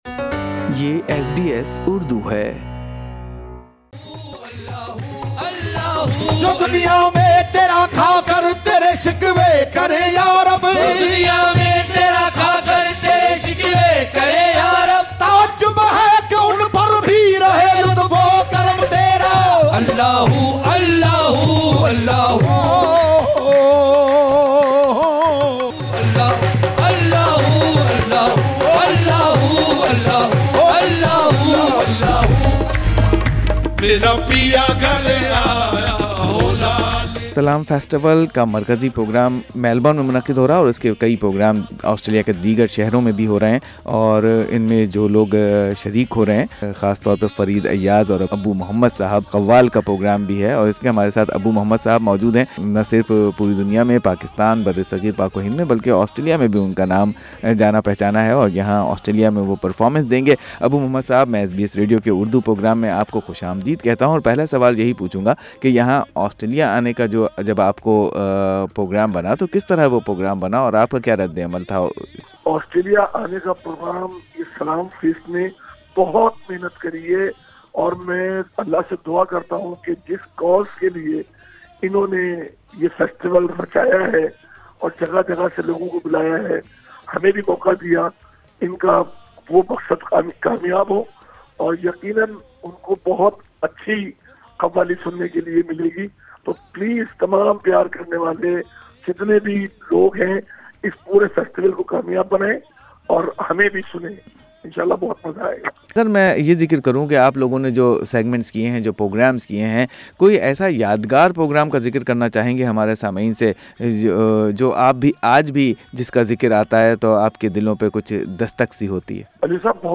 Listen podcast (Audio interview) of leading Qawaal Abu Muhammed where he is sharing interesting stories of reaction of international audiences.